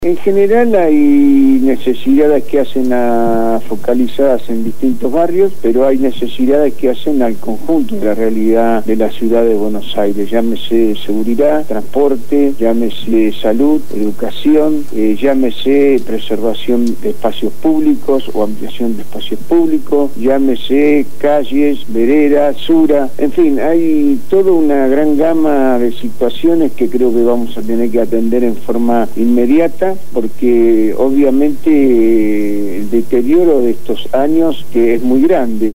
Lo afirmó Juan Carlos Dante Gullo, diputado nacional del Frente Para la Victoria y candidato a Legislador de la Ciudad de Buenos Aires por esa fuerza política en las próximas elecciones del 10 de julio de 2011, donde también se elige Jefe y Vicejefe de Gobierno y a los integrantes de las Juntas Comunales en las 15 Comunas porteñas, quien fue entrevistado en el programa «Punto de Partida» de Radio Gráfica FM 89.3